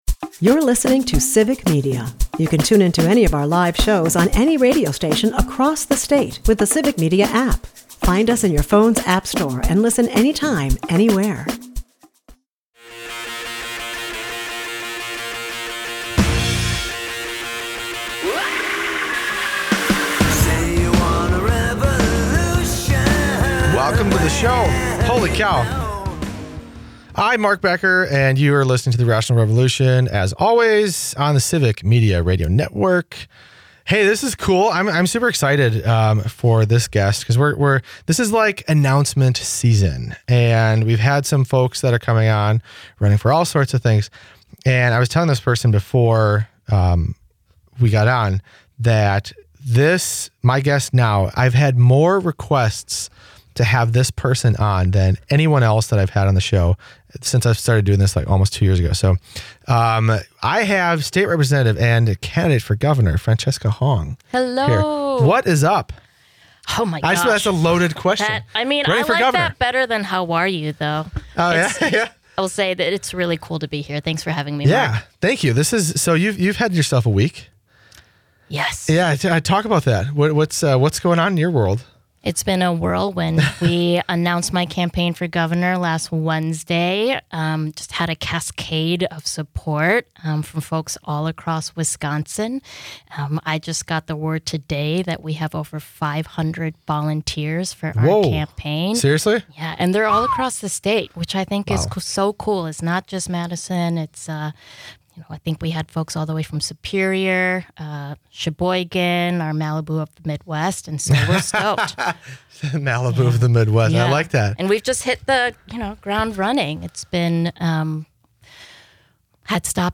Fresh off her announcement that she is running for Governor, State Rep Francesca Hong joins the show. She talks about why this is the time for a movement, and how her background in the service industry is uniquely qualifying for this moment.